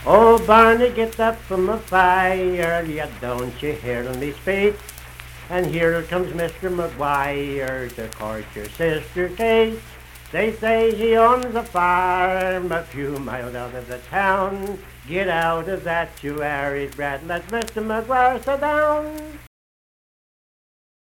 Unaccompanied vocal music
Verse-refrain 1(8). Performed in Hundred, Wetzel County, WV.
Voice (sung)